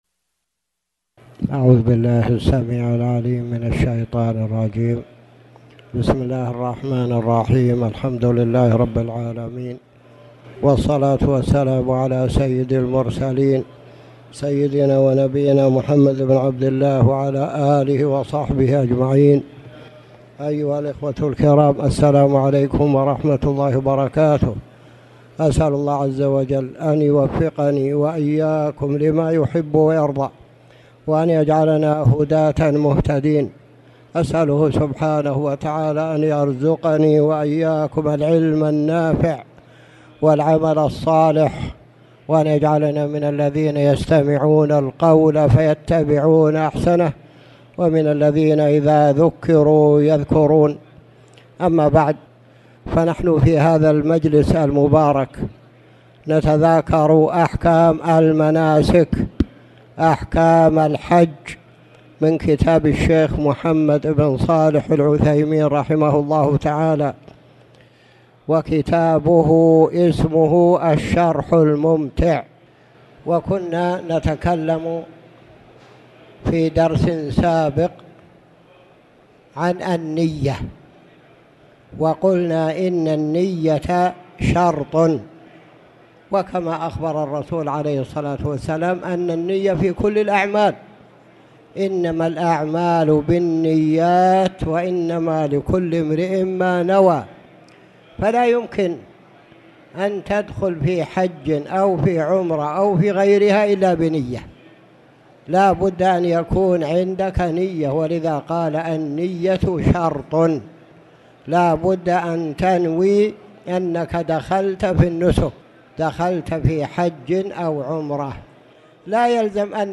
تاريخ النشر ١٣ ذو القعدة ١٤٣٨ هـ المكان: المسجد الحرام الشيخ